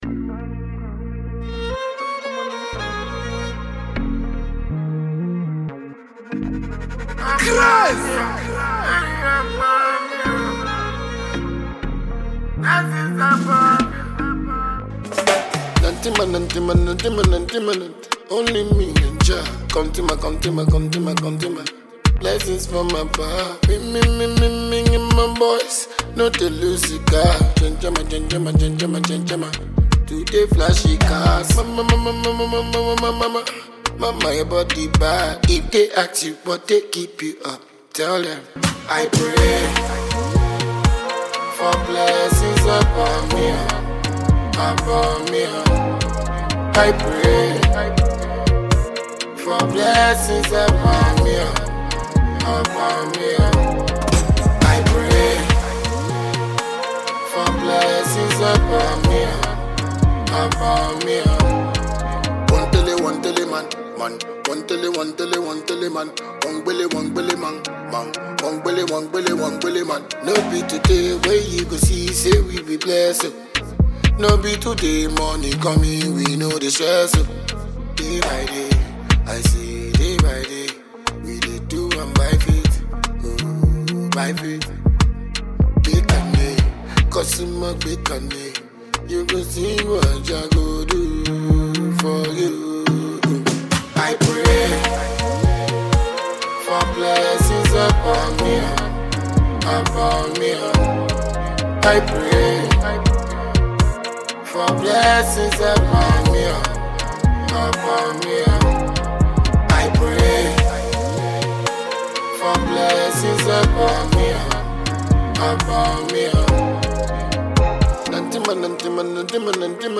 Ghanaian dancehall musician